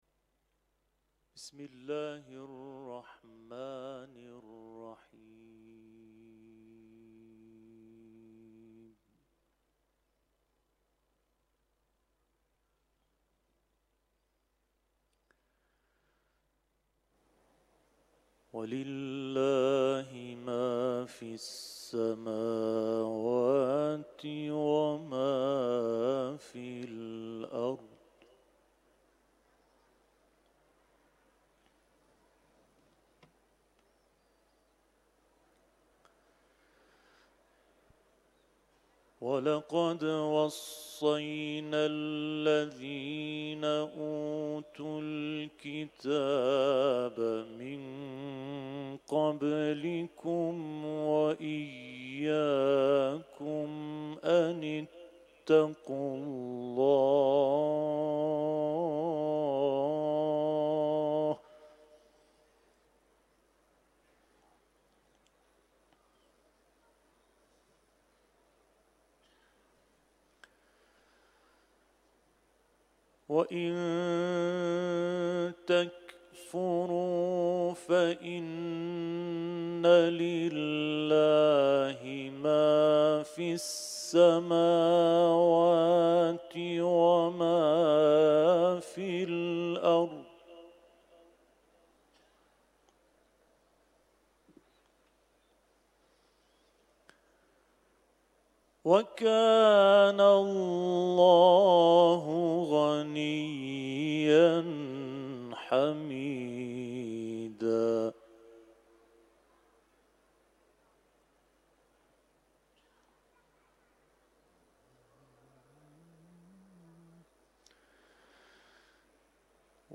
تلاوت ، سوره نساء